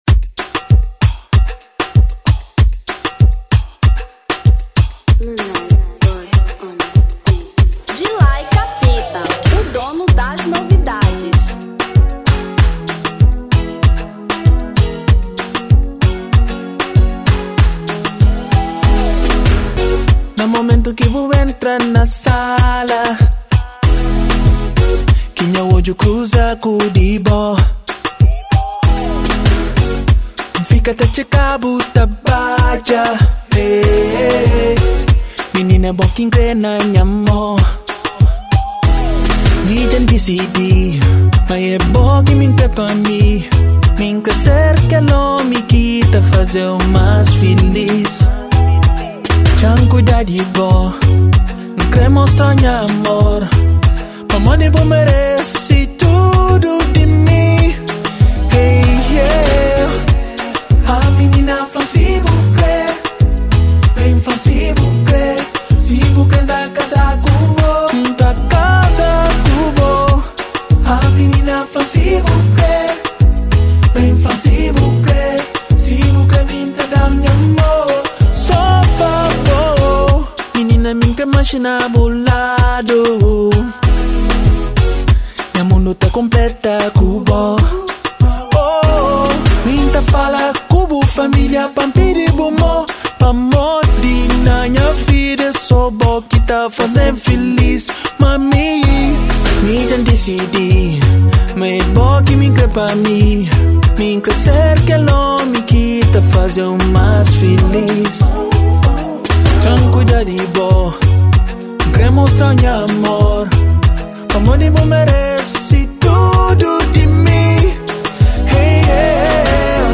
Kizomba 1995